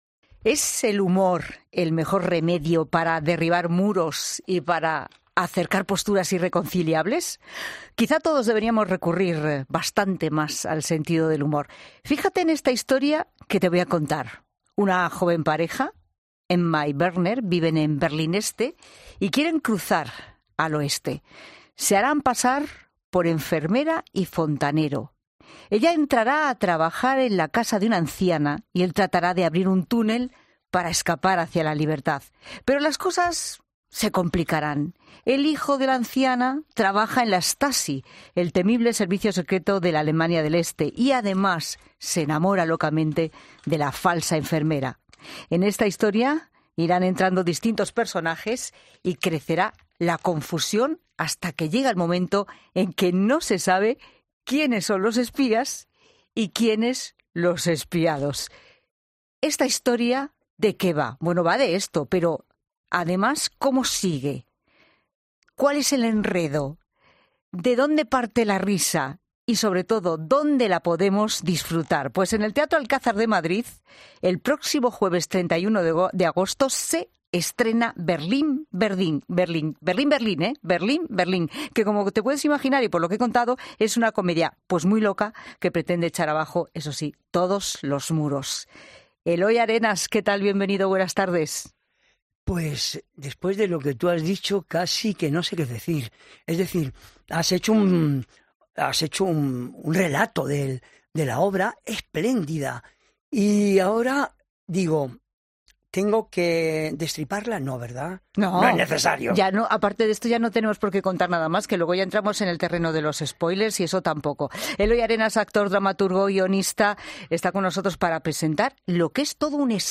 El actor, dramaturgo y guionista ha pasado por los micrófonos de COPE para presentarnos la obra.